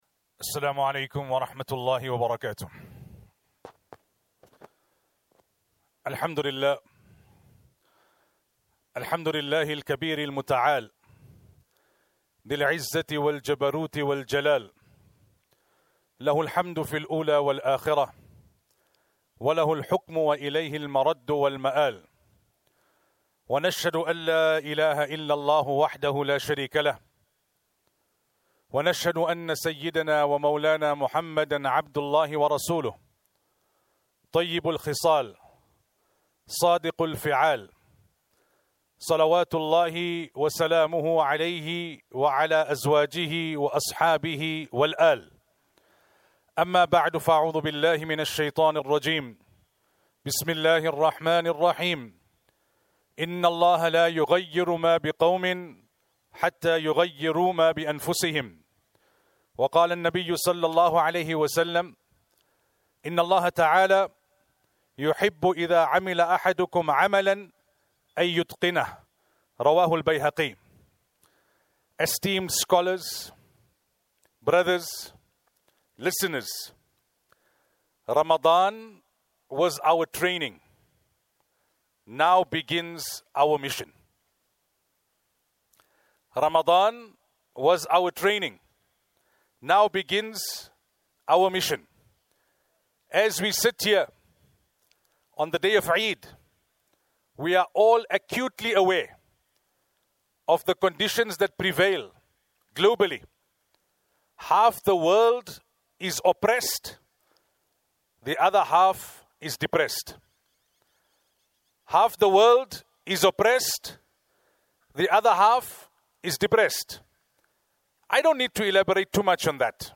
Five Point Plan to Transform Yourself and the Ummah – Eid Lecture